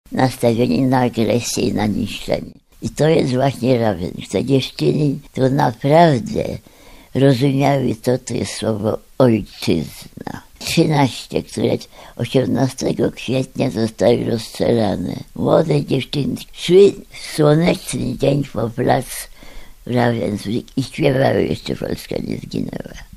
Wanda Półtawska wspomina, że Niemcy znęcali się nad więźniarkami i rozstrzeliwali je niemal do dnia ewakuacji obozu: